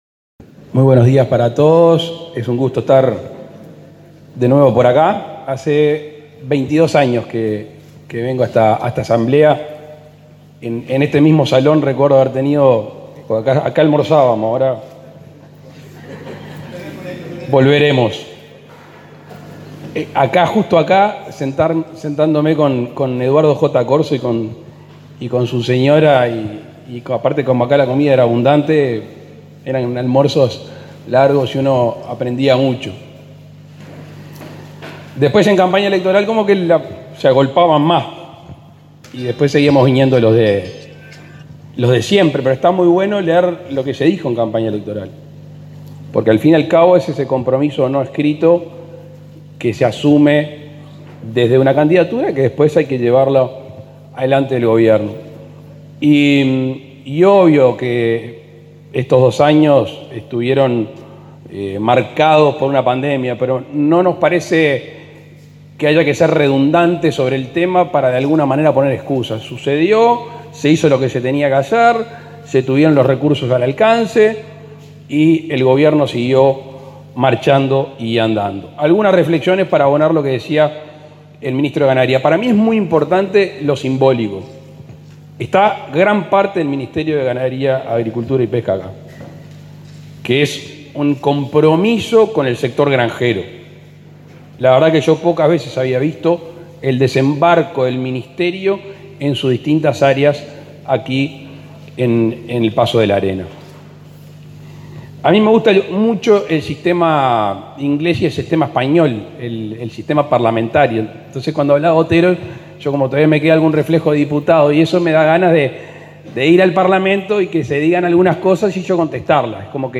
Palabras del presidente Luis Lacalle Pou
El presidente Luis Lacalle Pou participó este domingo 5 de la Asamblea Anual de la Sociedad de Fomento y Defensa Agraria.